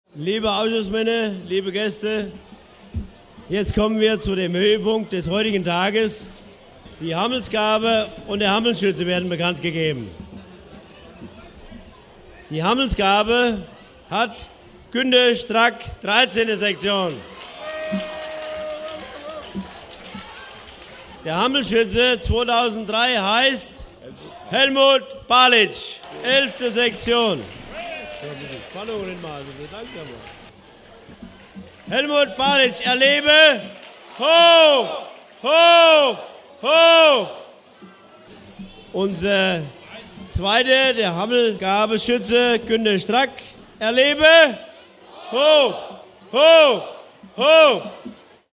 Bekanntgabe des Hammelschützen im MP3-Format!